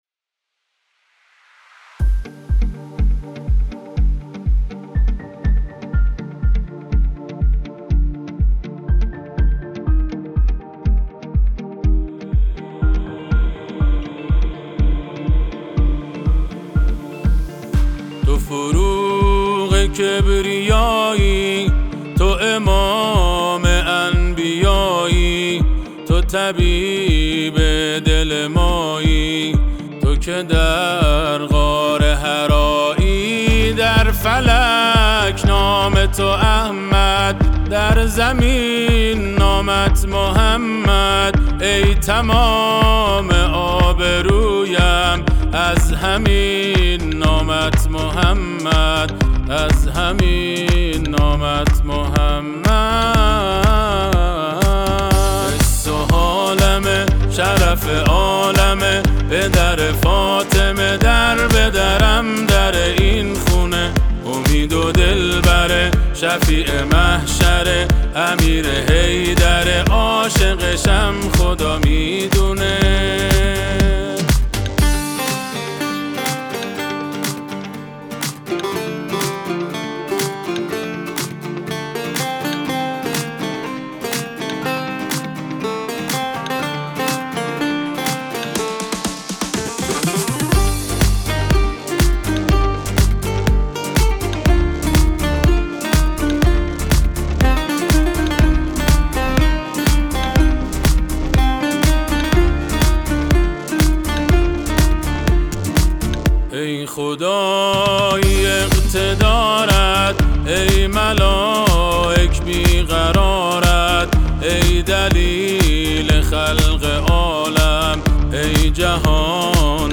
آثار آهنگین مذهبی